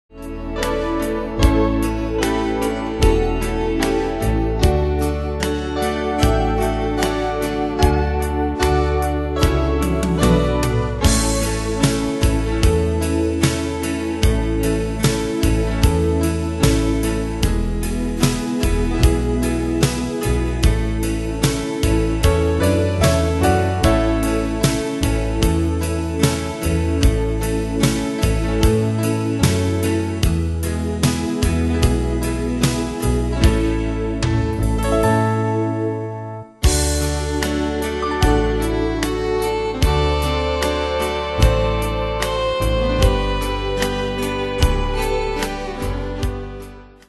Style: Country Année/Year: 1995 Tempo: 75 Durée/Time: 4.03
Danse/Dance: Ballade Cat Id.
Pro Backing Tracks